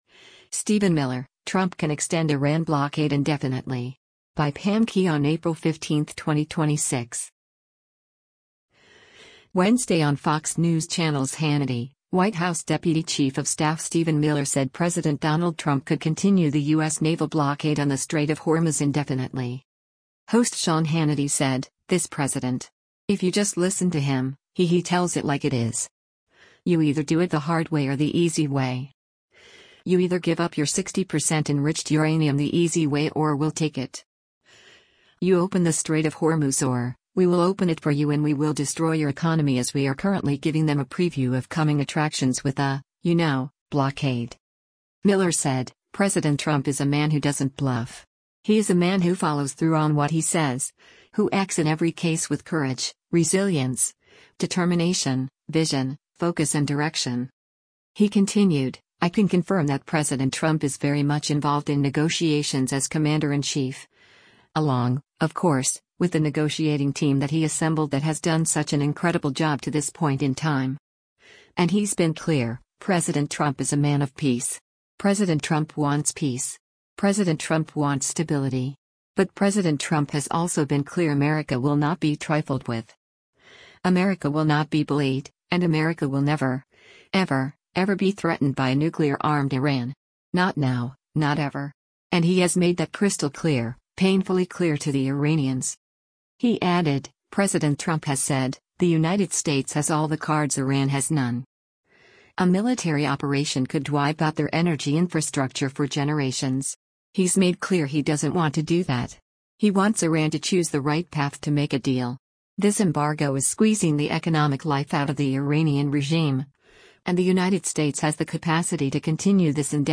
Wednesday on Fox News Channel’s “Hannity,” White House deputy chief of staff Stephen Miller said President Donald Trump could continue the U.S. naval blockade on the Strait of Hormuz “indefinitely.”